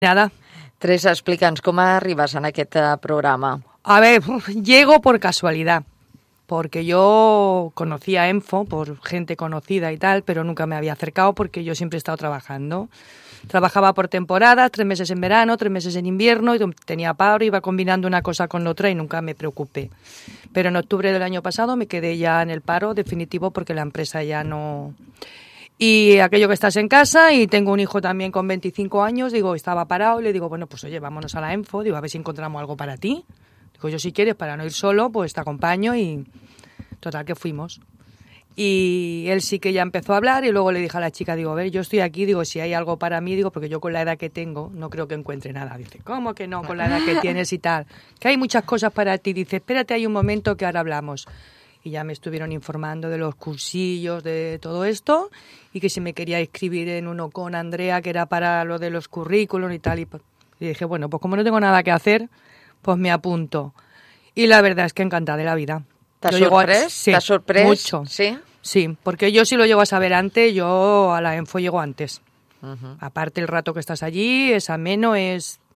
Aquest dilluns, al “Posa’t les piles” de Ràdio Mollet, vam parlar del programa Innovadors i volem compartir amb vosaltres dos talls de veu de dues de les participants d’aquest projecte.